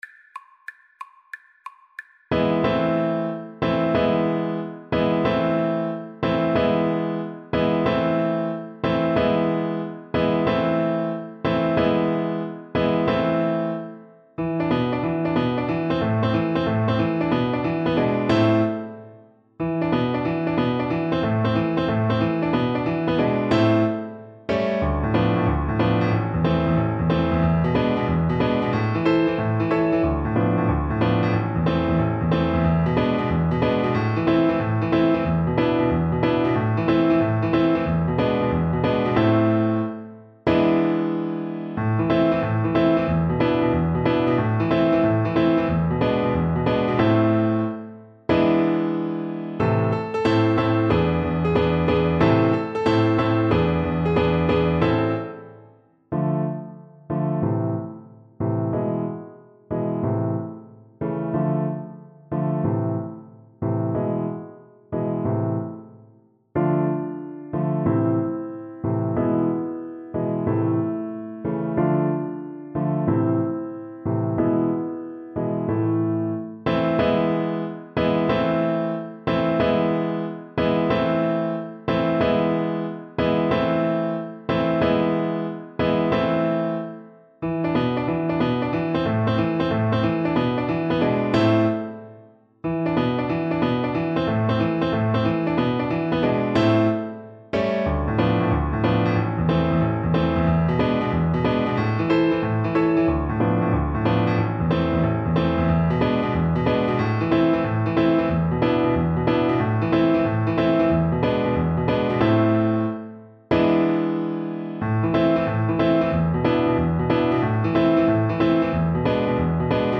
Play (or use space bar on your keyboard) Pause Music Playalong - Piano Accompaniment Playalong Band Accompaniment not yet available transpose reset tempo print settings full screen
Violin
Pizzica di San Vito is an Italian traditional tune.
Pizzica music is known for its fast-paced rhythms, intricate melodies, and infectious energy.
A minor (Sounding Pitch) (View more A minor Music for Violin )
6/8 (View more 6/8 Music)
Molto allegro .=c.184